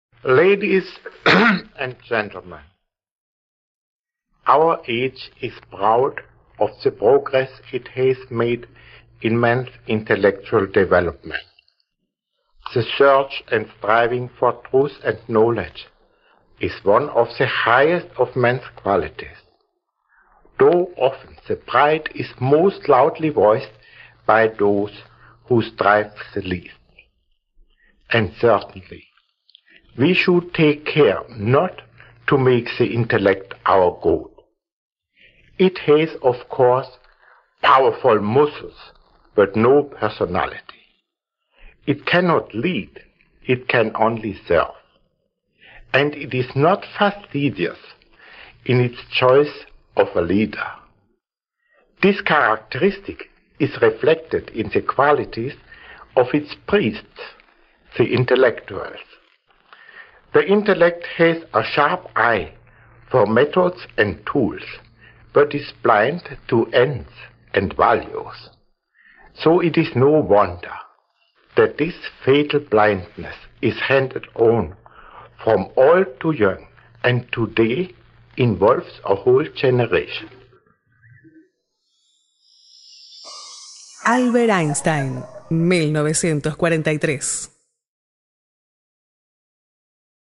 아인슈타인의 연설 시작 부분 (1943년 4월 11일) 유대인 구호 기금을 위한 연설 (아르헨티나 라 플라타 국립대학교 라디오 녹음)